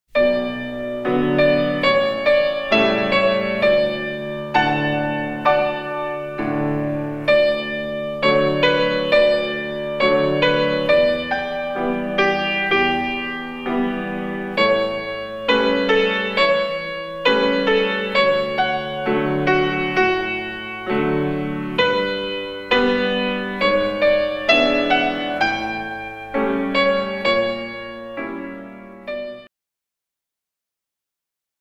In 2